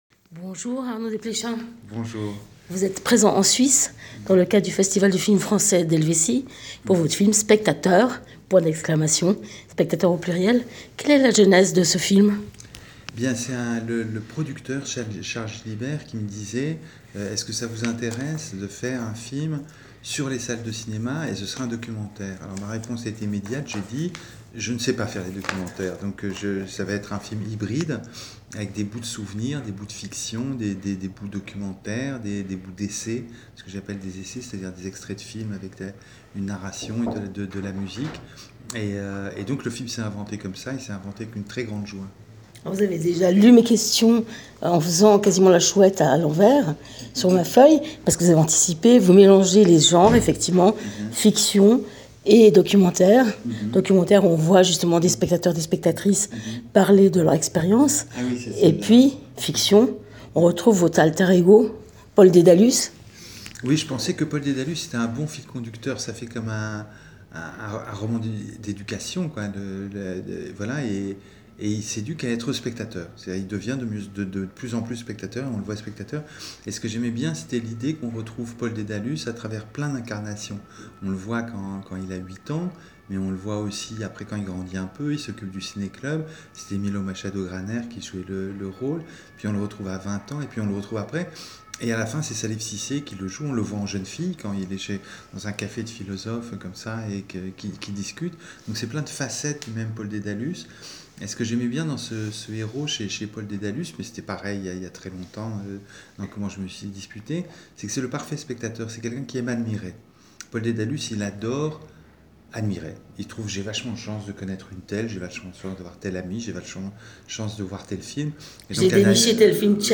Spectateurs ! La leçon de cinéma magistrale d’Arnaud Desplechin. Rencontre - j:mag